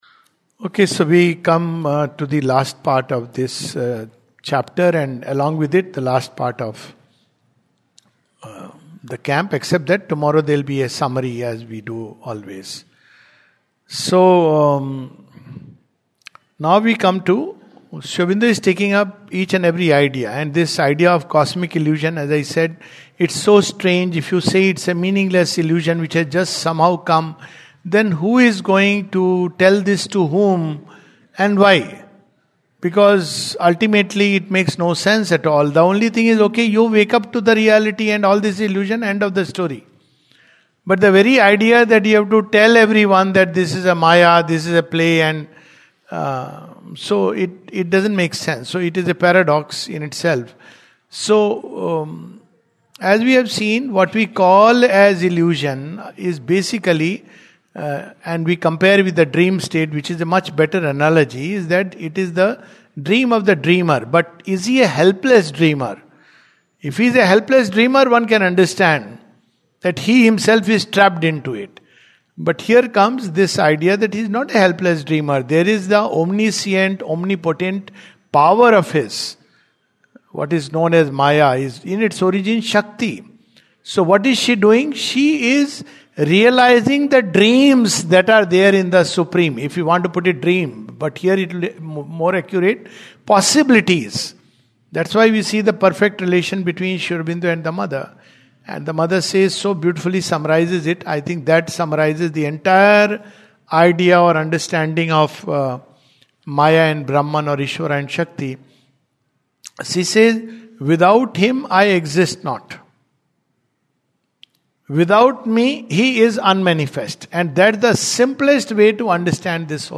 The Life Divine, 27th February 2026, Session # 06-12 at Sri Aurobindo Society, Pondicherry - 605002, India. We continue with the Chapter on Cosmic Illusion. A talk